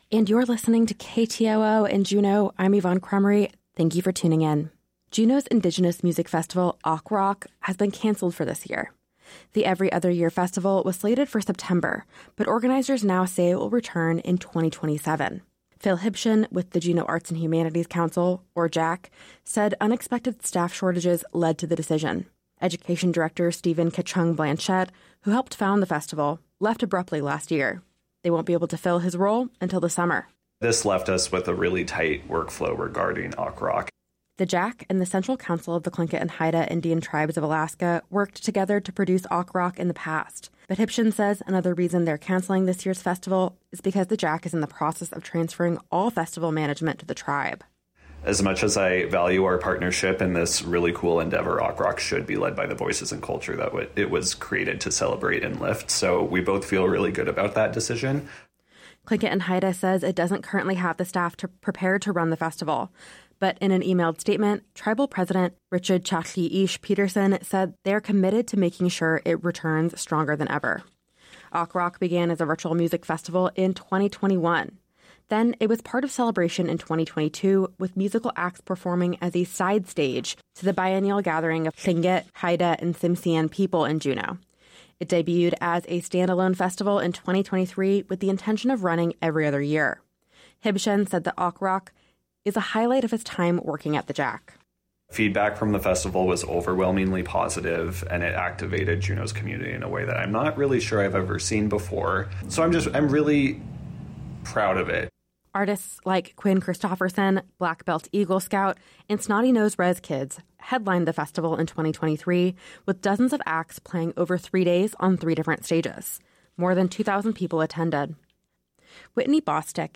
Newscast – Friday, Jan. 10, 2025 - Areyoupop